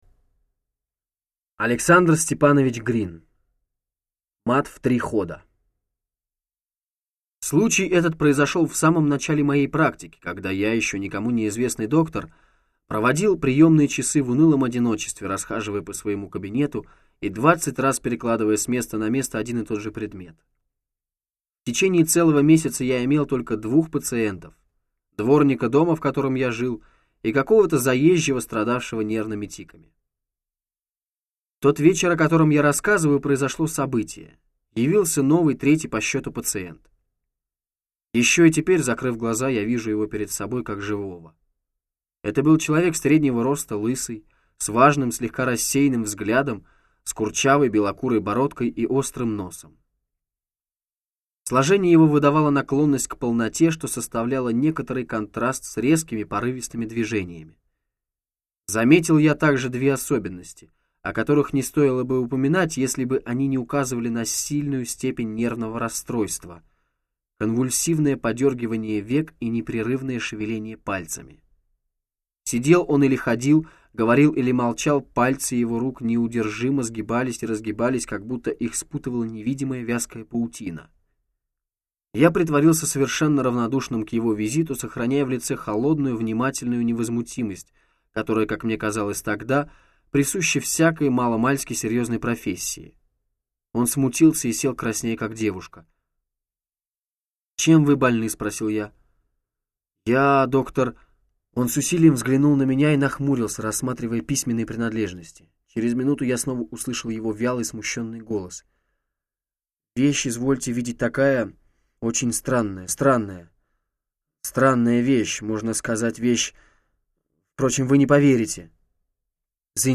Аудиокнига Рассказы: Гладиаторы. Лошадиная голова. Мат в три хода. Судьба взятая за рога. Таинственная пластинка | Библиотека аудиокниг